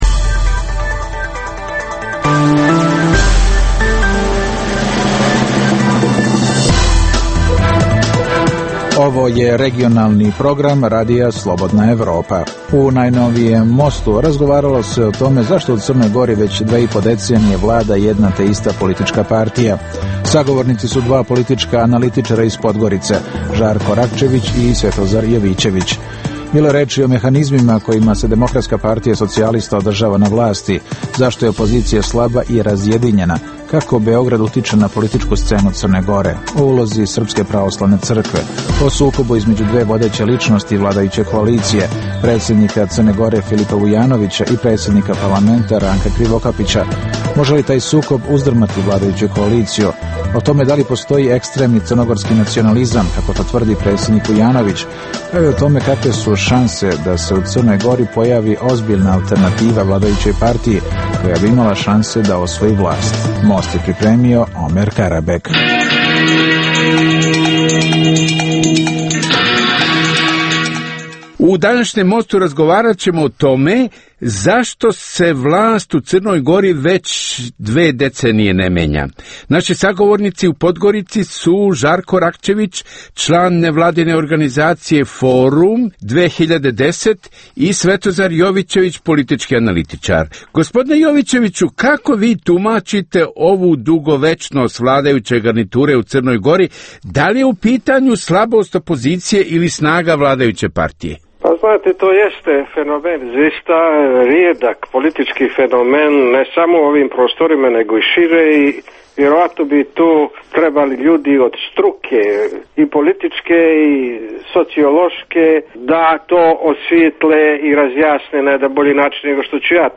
u kojem ugledni sagovornici iz regiona diskutuju o aktuelnim temama. Drugi dio emisije čini program "Pred licem pravde" o suđenjima za ratne zločine na prostoru bivše Jugoslavije.